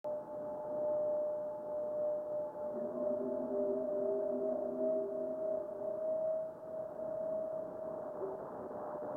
This meteor occurs during some ionospheric interference.